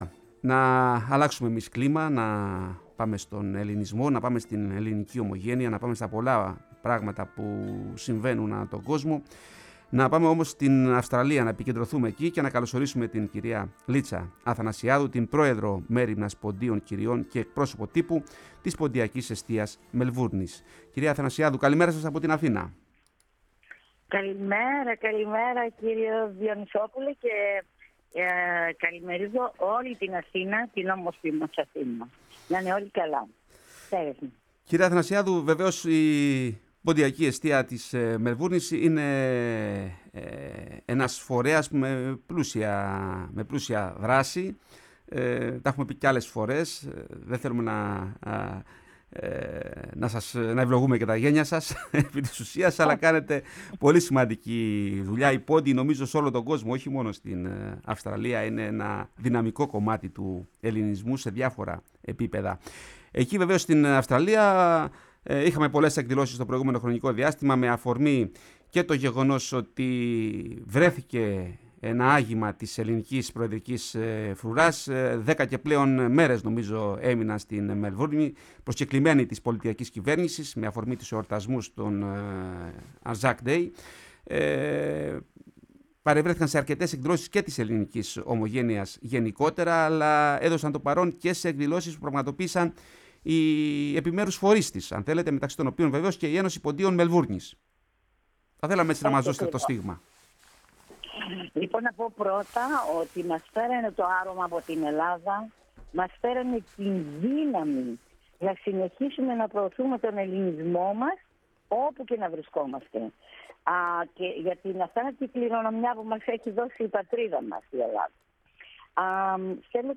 Ακούστε ολόκληρη τη συνέντευξη στο ηχητικό της ανάρτησης. Η ΦΩΝΗ ΤΗΣ ΕΛΛΑΔΑΣ Η Ελλαδα στον Κοσμο ΟΜΟΓΕΝΕΙΑ